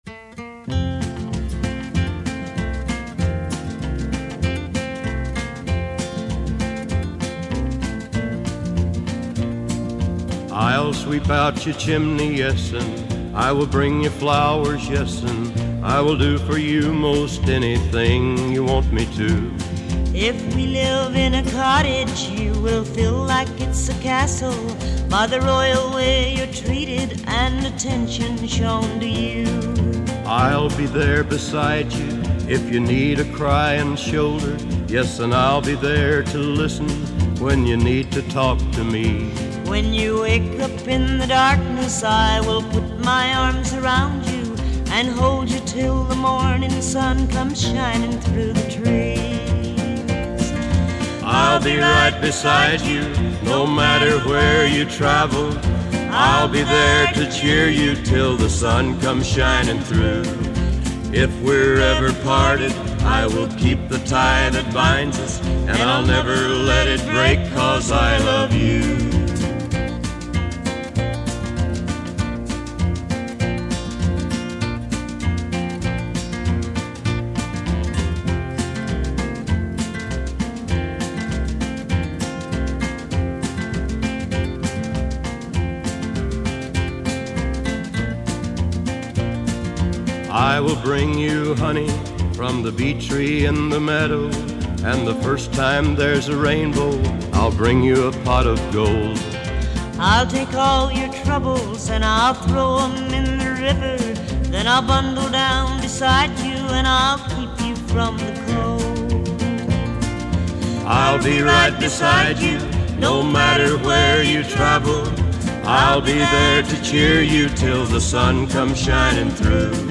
Жанр: Soundtrack, Folk, World, & Country, Stage & Screen